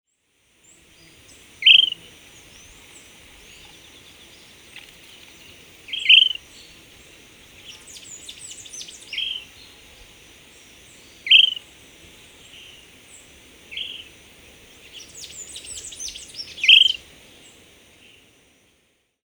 Myiarchus ferox
Nome em Inglês: Short-crested Flycatcher